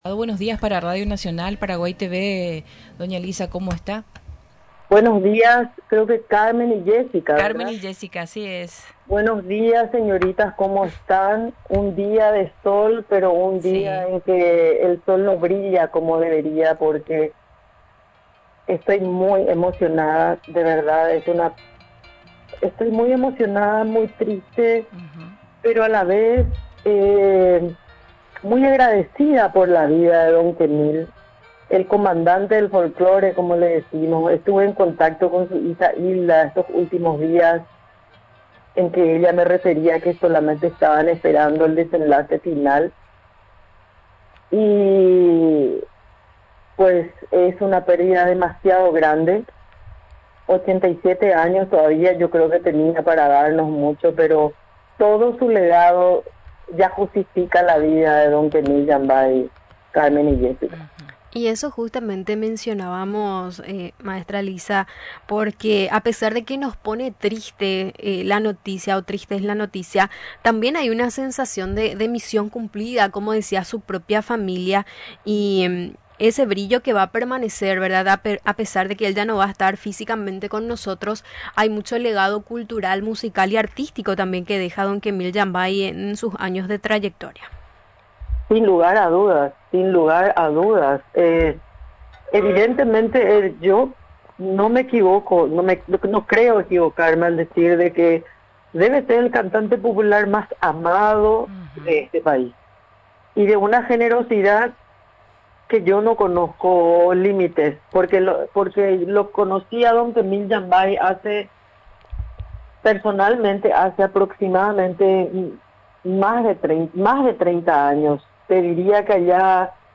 La entrevistada subrayó la importancia de valorar a los referentes culturales en vida y lamentó que a veces los paraguayos sean cautelosos al demostrar el afecto y la admiración que sienten por sus artistas. Mencionó que la familia del músico siente el cariño de la gente de todo el mundo y que pueden descansar con la satisfacción de haber cumplido su misión junto a un hombre tan excepcional.